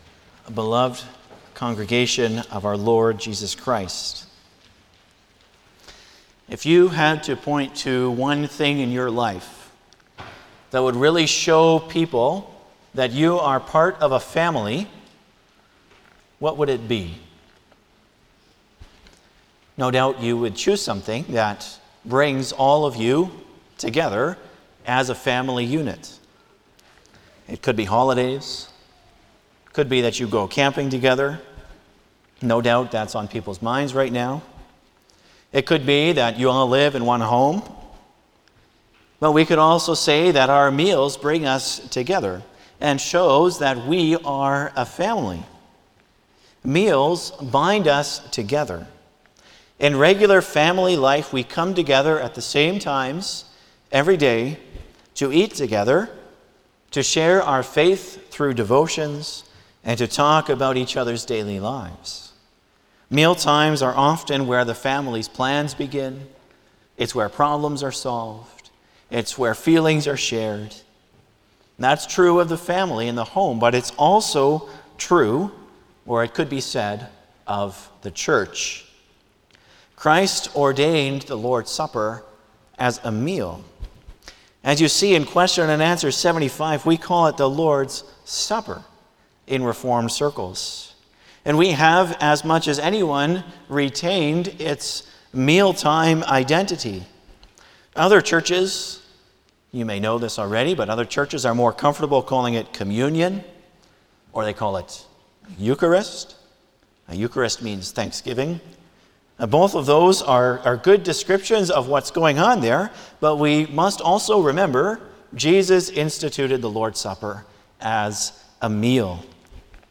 07-Sermon.mp3